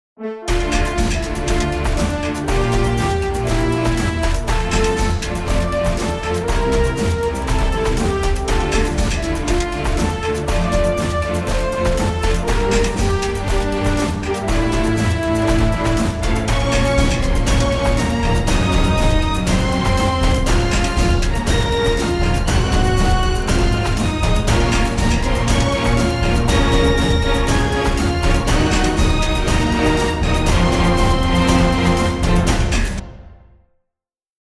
Full strings Staccato.